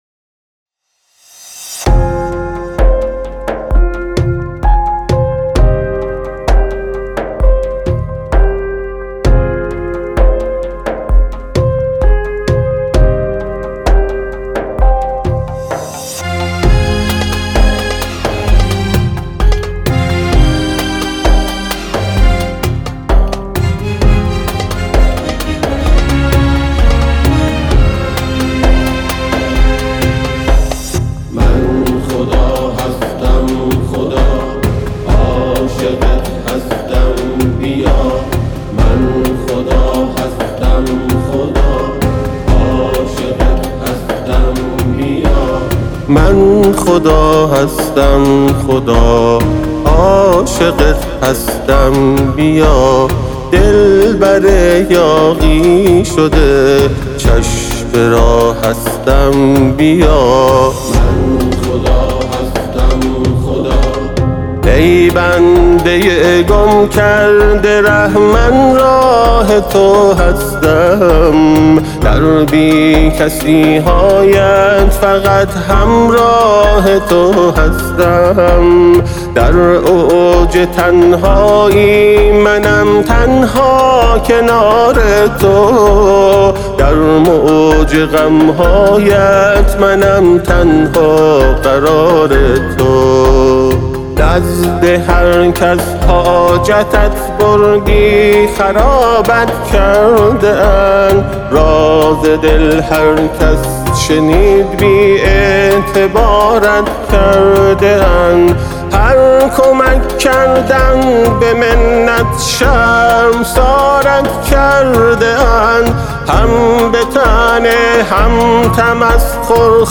آهنگ ایرانی